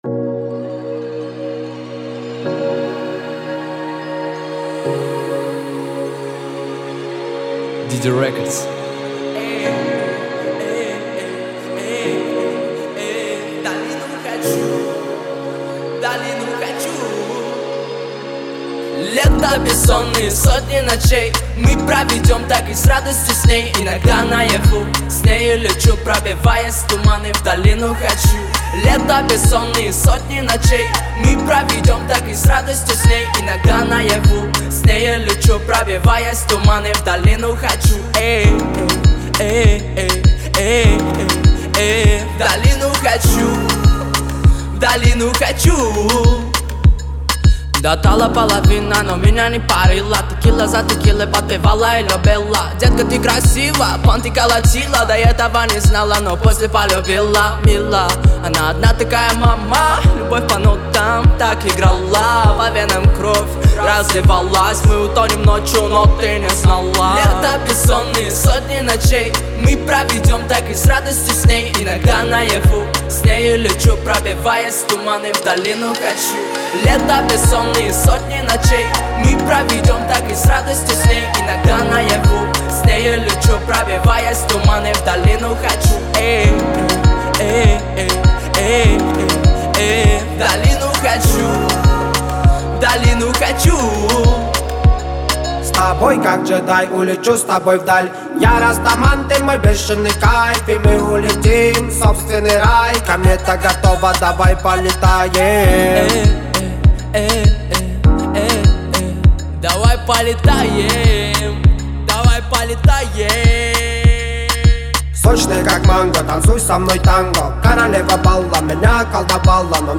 это яркая и мелодичная песня в жанре поп
дуэтом